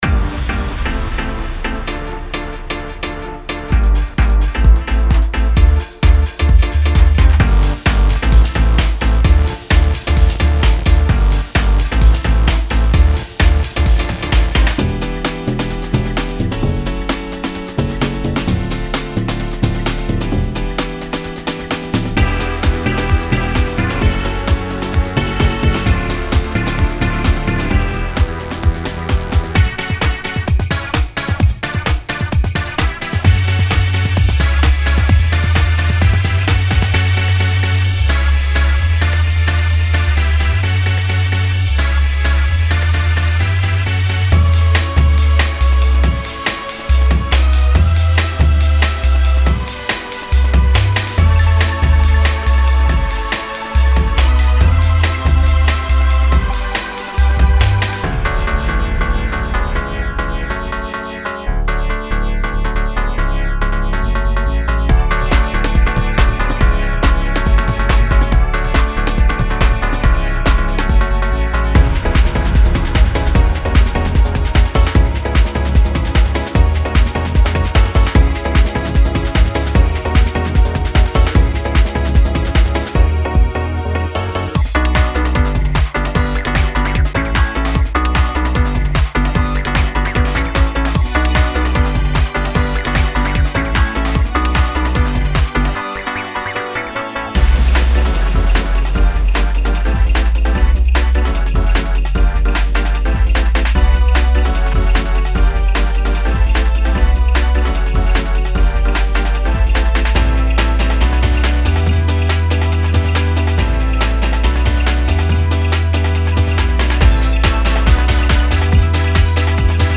Genre:Classic House
本パックでは、伝統的なコード進行に加え、単一のサンプルコード（通常はmin7）を異なるピッチで演奏する手法も採用。
57 Rave Synth Loops
20 Piano Loops